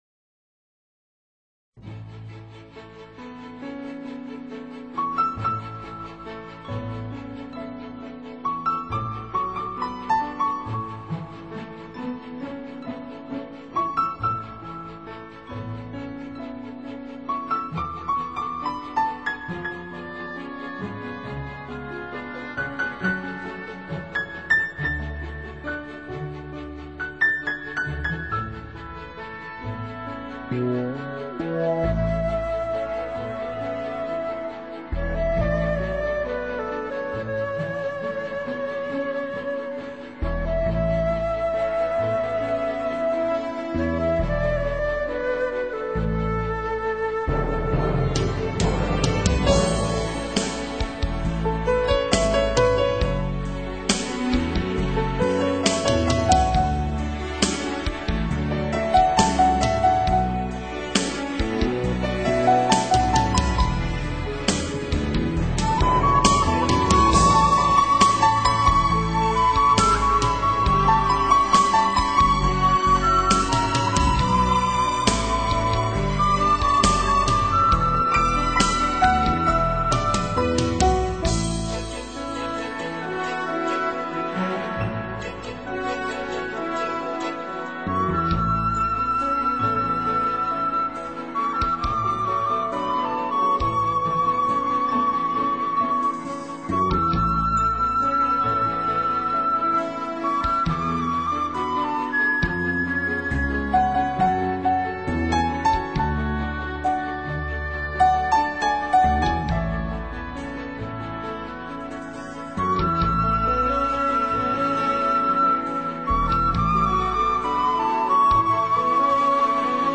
如此绝美浪漫